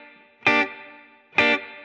DD_TeleChop_130-Gmin.wav